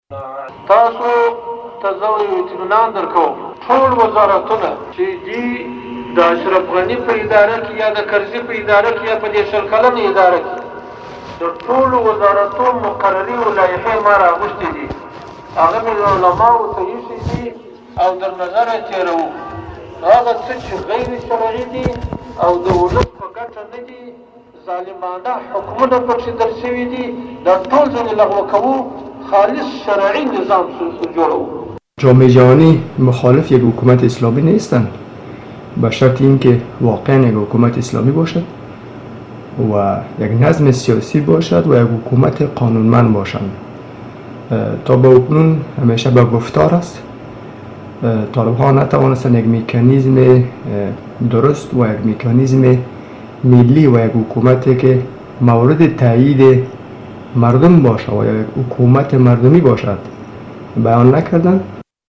به گزارش رادیو تاجیکی صدای خراسان به نقل ازطلوع نیوز، هبت‌الله آخوندزاده رهبر طالبان با ایراد سخنرانی در مسجد عیدگاه قندهار گفت: تمامی قانون های که در حکومت های گذشته افغانستان تصویب و عملی شده و به گفته وی شرعی نبوده اند، لغو خواهد شد.